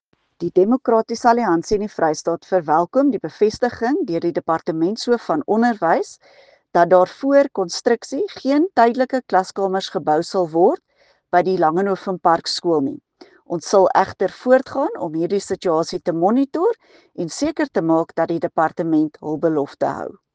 Afrikaans soundbites by Dulandi Leech MPL